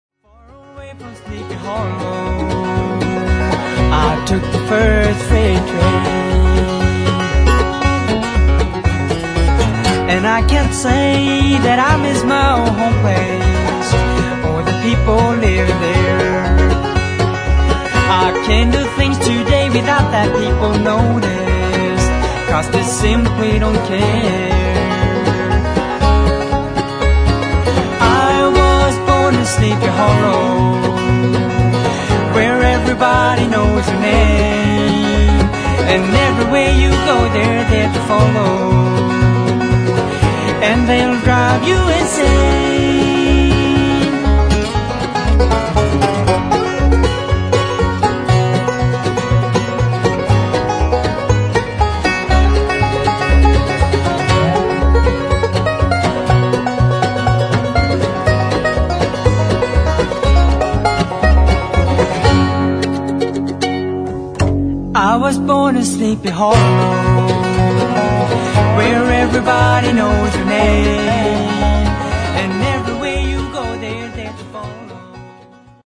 Nu Europamästare i bluegrass!
Bluegrassbandet
dobro
gitarr o sång